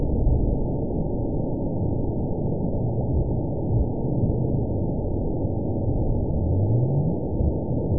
event 917699 date 04/13/23 time 04:02:53 GMT (2 years ago) score 9.51 location TSS-AB05 detected by nrw target species NRW annotations +NRW Spectrogram: Frequency (kHz) vs. Time (s) audio not available .wav